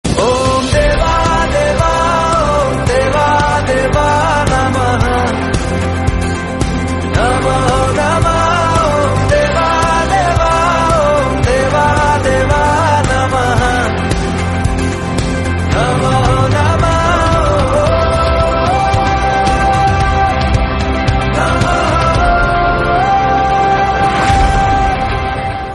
Bollywood Ringtones